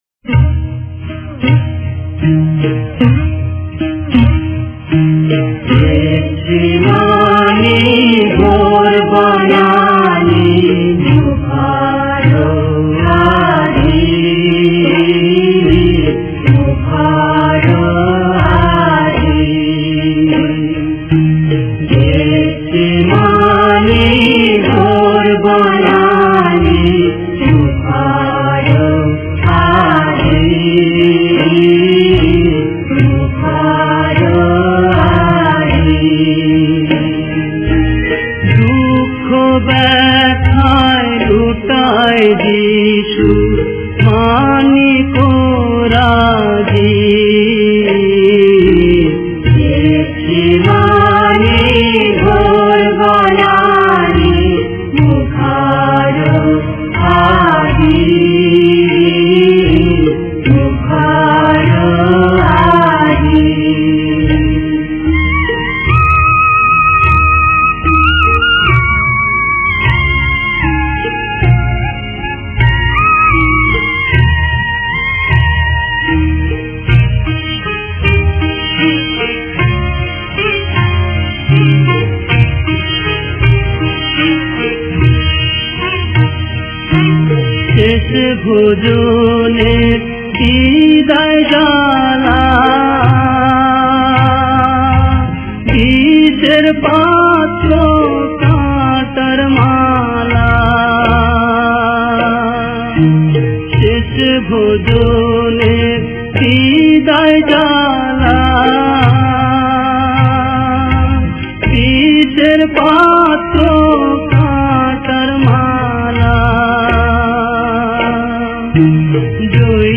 Devotional Hymns